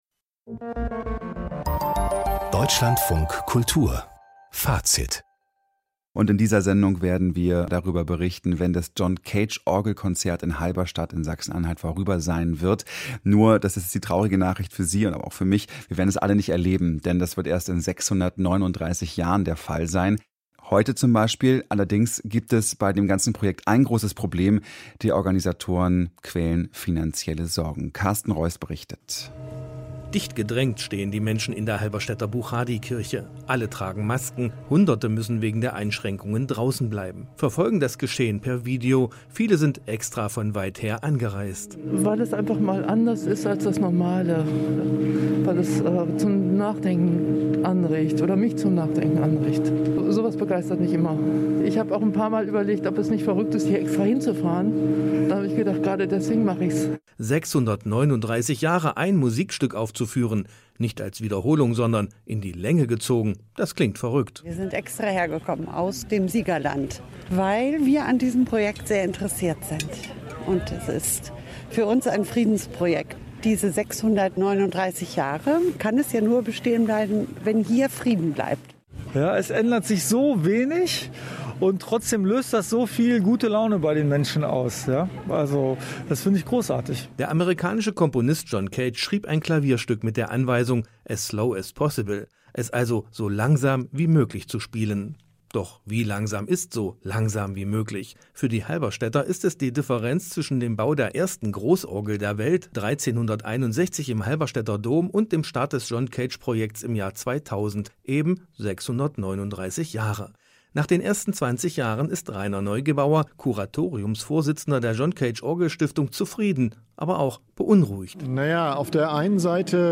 In fast allen Beiträgen war zu erfahren, dass das Projekt an seine finanziellen Grenzen stößt. Hier kann ein leicht gekürzter Beitrag des DLF nachgehört werden.